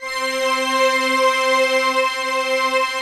SI1 CHIME0DR.wav